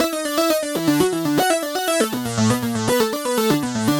Index of /musicradar/french-house-chillout-samples/120bpm/Instruments
FHC_Arp C_120-E.wav